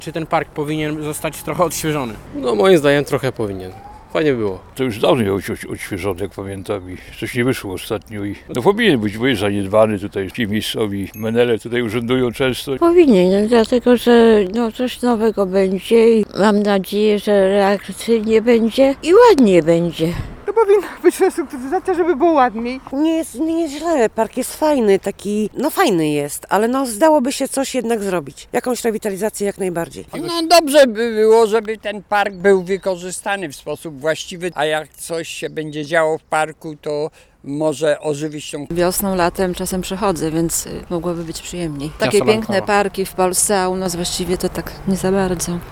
Zdaniem zielonogórzan – park Tysiąclecia potrzebuje rewitalizacji: